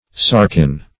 sarkin - definition of sarkin - synonyms, pronunciation, spelling from Free Dictionary
Sarkin \Sar"kin\, n. [Gr. sa`rx, sa`rkos, flesh.]